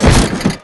compress_truck_1.wav